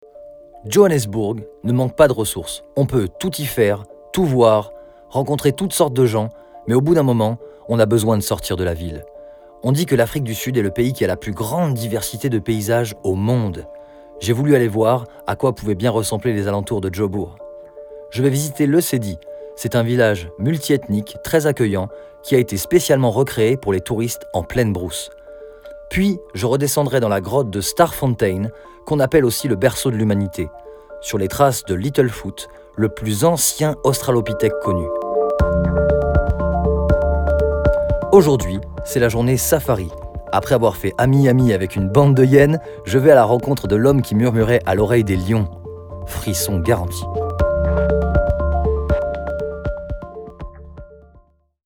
Sprechprobe: eLearning (Muttersprache):
I am a singer and comedian,i am very passionned by voice over, i can do imitations,cartoon also adapt my voice tone to the customers requests.